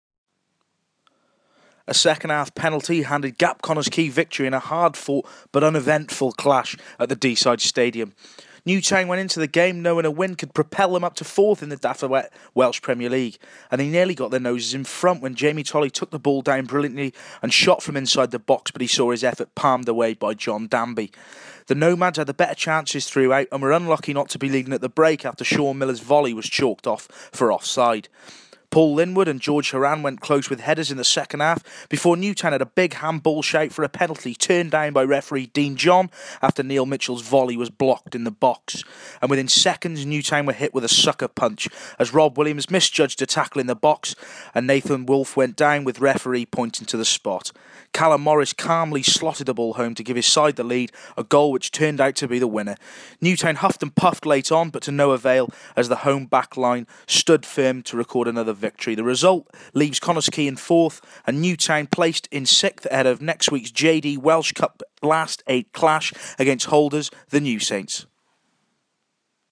Audio report of today's 1-0 defeat to GAP Connah's Quay